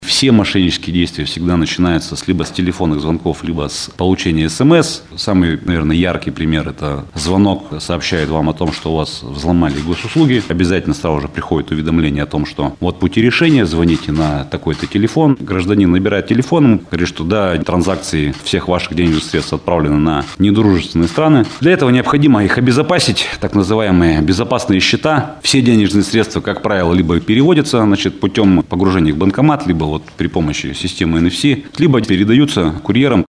За первое полугодие в городе было зарегистрировано 1085 киберпреступлений, — рассказал начальник МВД Екатеринбурга Виктор Позняк на пресс-конференции «ТАСС-Урал».